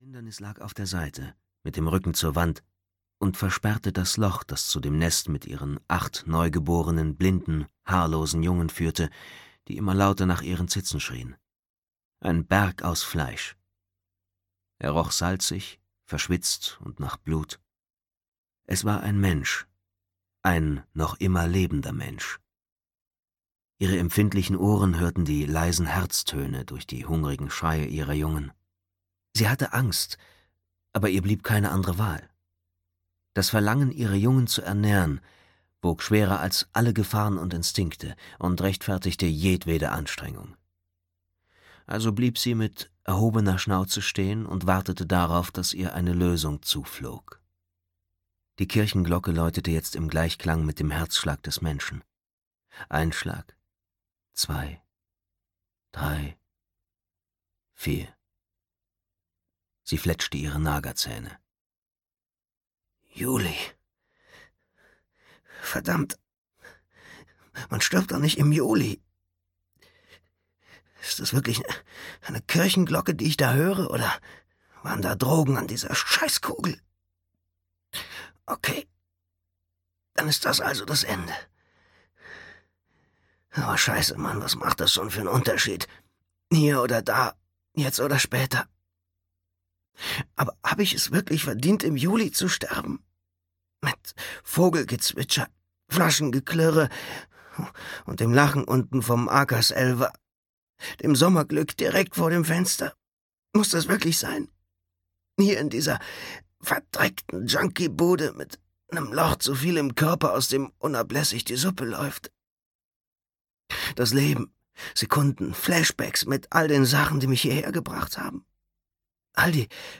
Larve (DE) audiokniha
Ukázka z knihy